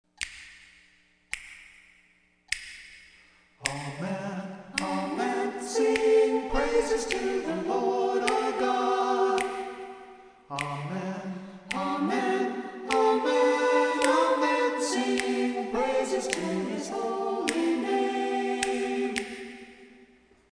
female vocals
Choral    Pop    Home